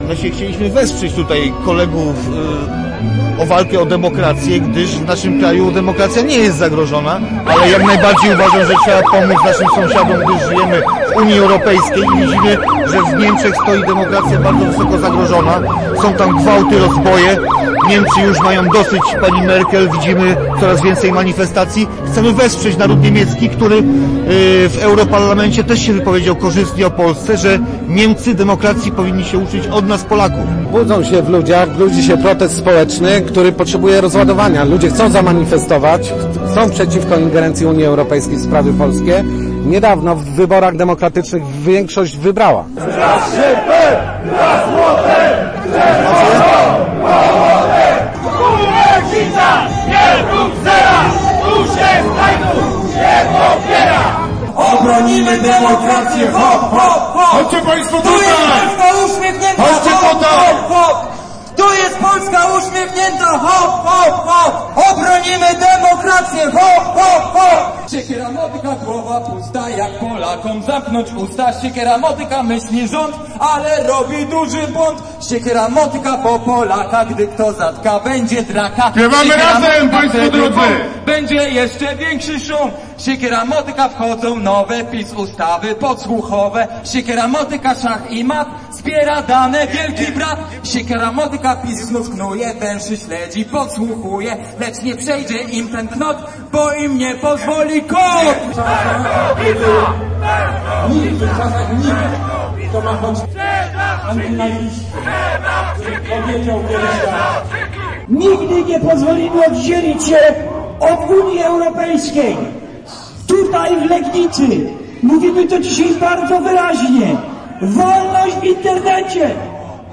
Przez prawie godzinę centrum miasta zamieniło się w polityczny wiec.
manigestacja.mp3